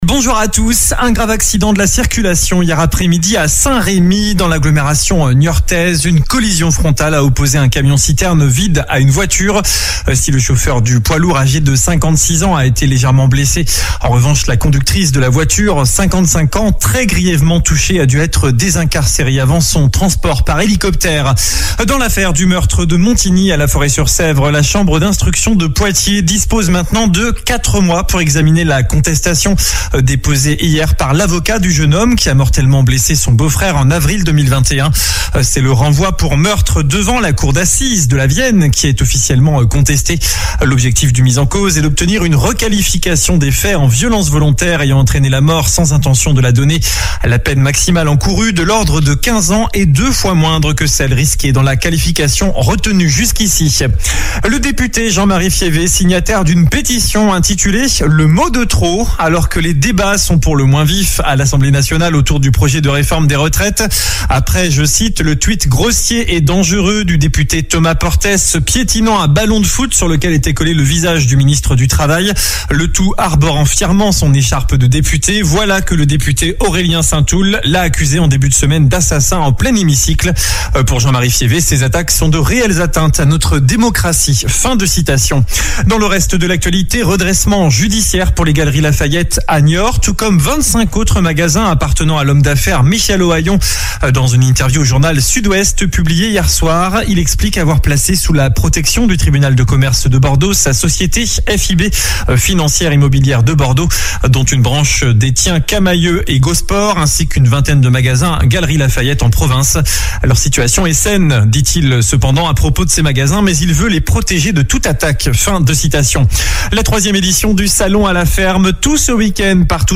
JOURNAL DU SAMEDI 18 FEVRIER ( MIDI )